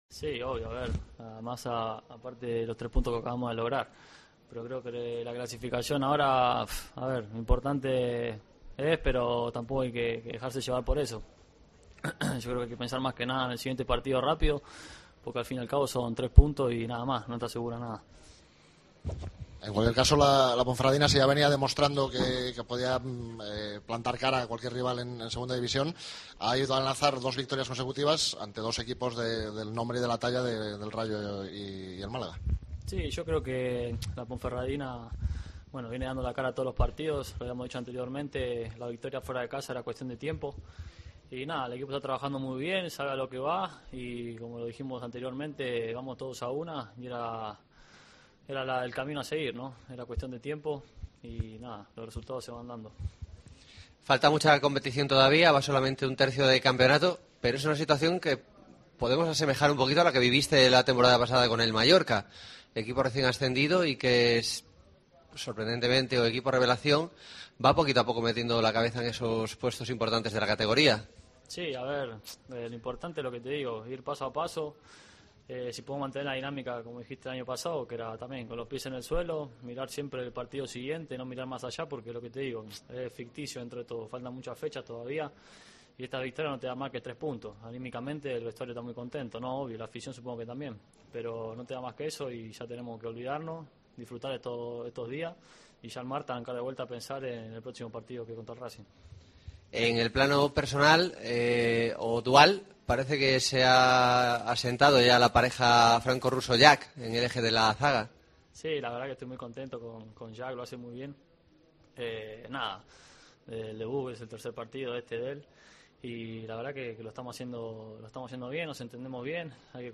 POSTPARTIDO
Escucha aquí las declaraciones de los dos jugadores blanquiazules